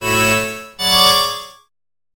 tango bandoneon_1
enfatizada_sample_bandoneon_snippet_fast-1.ogg